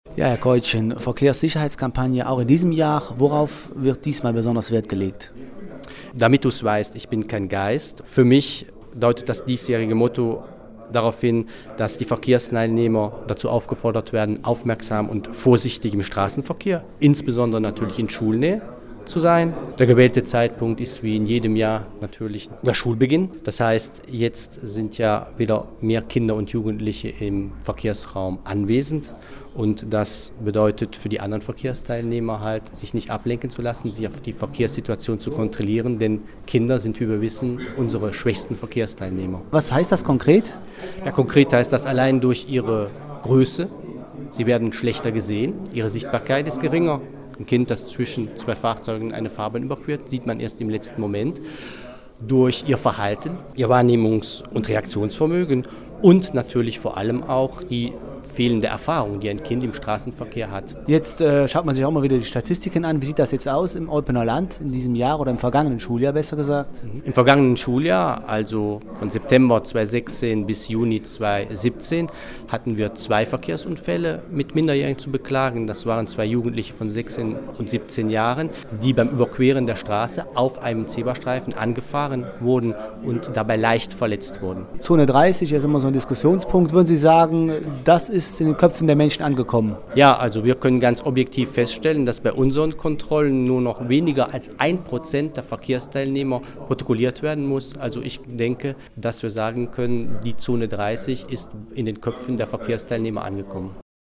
über die diesjährige Kampagne unterhalten